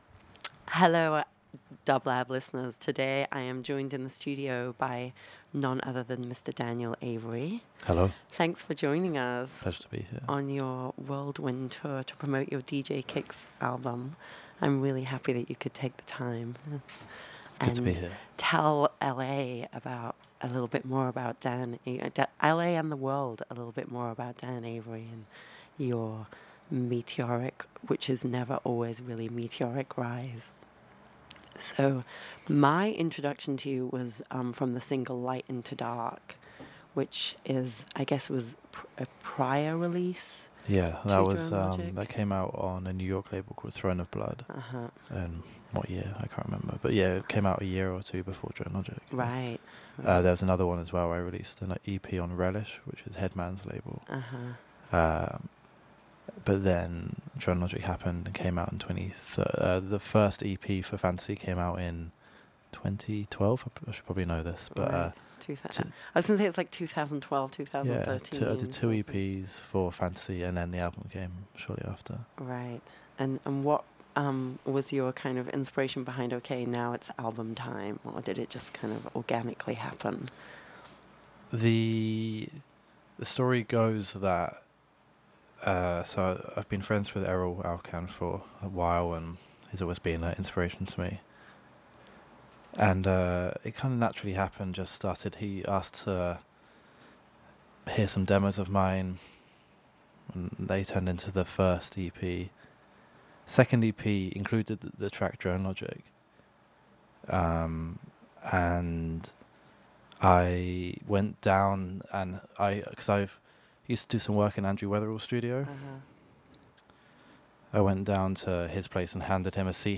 Disco/House